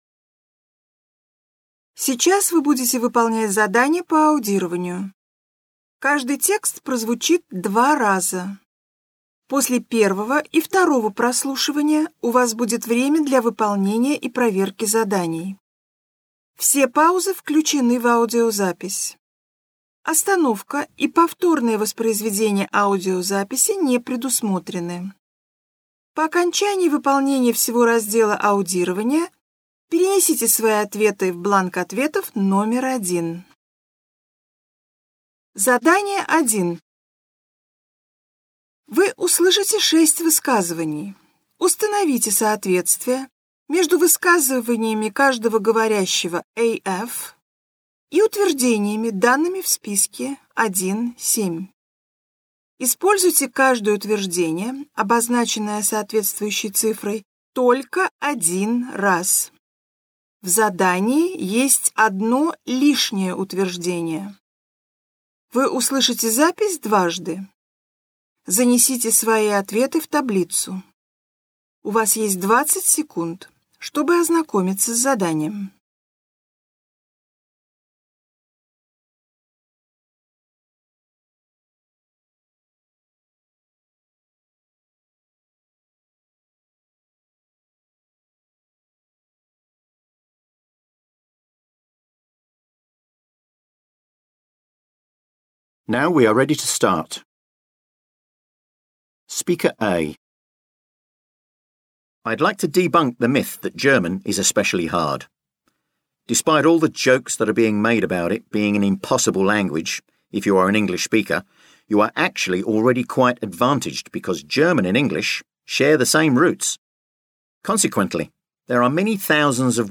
Вы услышите 6 высказываний.